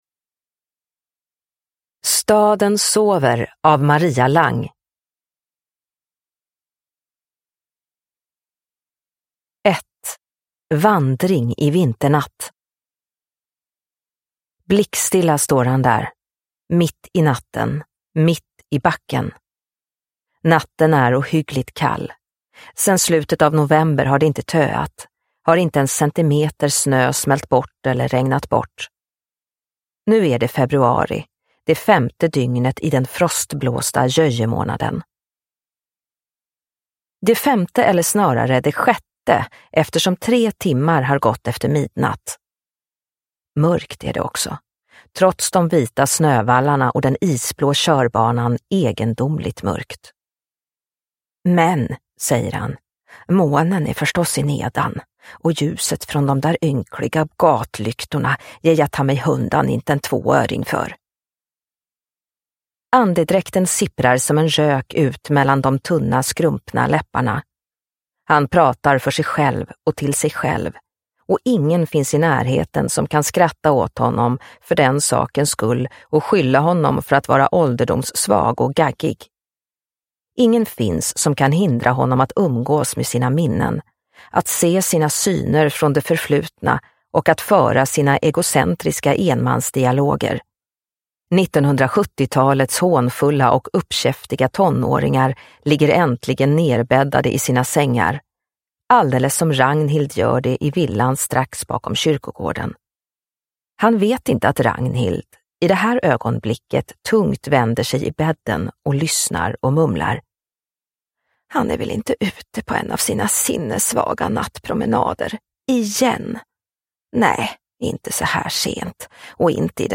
Staden sover – Ljudbok – Laddas ner